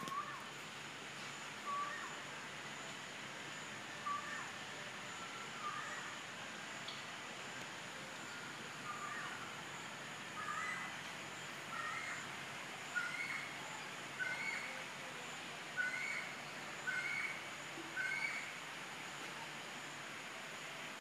Vanuit de jungle om ons heen komen allerlei onbekende geluiden. Zo is er een vogel die de hele tijd ‘for real!’ lijkt te roepen. Dat doet hij steeds harder, steeds hoger en geagiteerder, totdat alleen de beklemtoonde lettergreep overblijft: ‘Ie! Ie! Ie!’ Nadat hij even pauzeert om tot bedaren te komen, begint hij van voren af aan met een rustig en goed gearticuleerd ‘for real!’ Hier is een opname die ik met mijn mobieltje heb gemaakt, met excuses voor de gebrekkige kwaliteit: